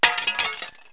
/ cdmania.iso / sounds / destruct / smlbreak.wav ( .mp3 ) < prev next > Waveform Audio File Format | 1996-04-15 | 10KB | 1 channel | 22,050 sample rate | 1 second
smlbreak.wav